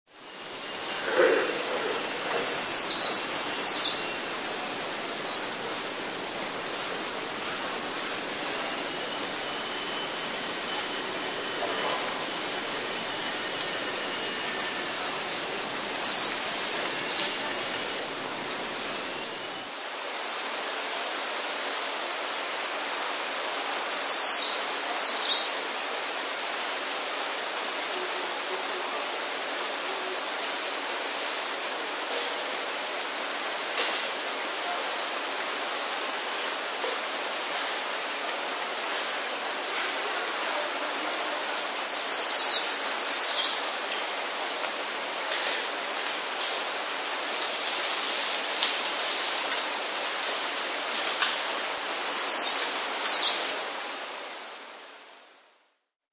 Przykłady śpiewu pełzaczy pochodzą z moich własnych nagrań.
Pełzacz leśny - Certhia familiaris
śpiewającego jeszcze we wrześniu.